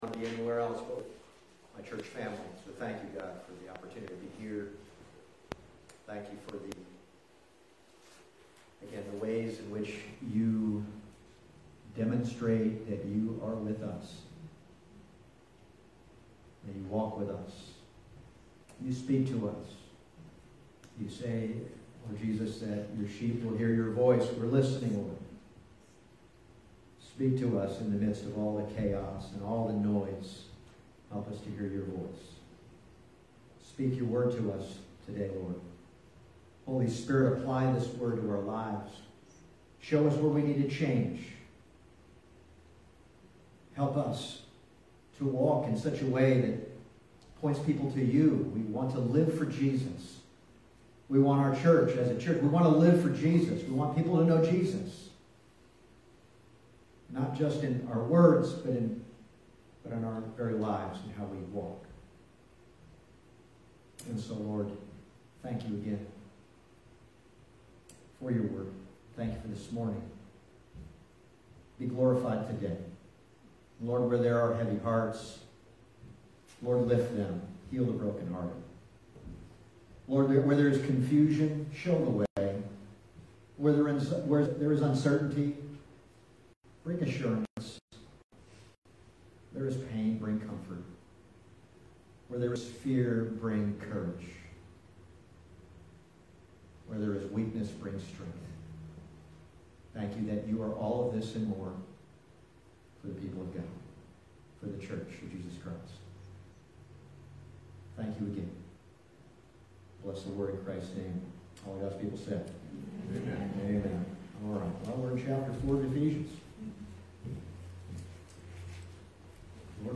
Ephesians Passage: Ephesians 4:25-32 Service Type: Sunday Morning « Put Off The Old Man Put On the New Man Immanuel